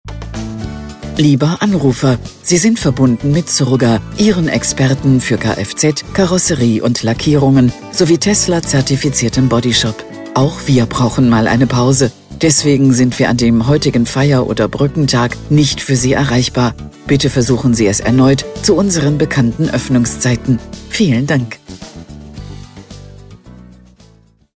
Telefonansagen mit echten Stimmen – keine KI !!!
Ansage für Feiertage
Zurga-Feiertage.mp3